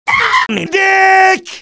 demoman_paincrticialdeath03.mp3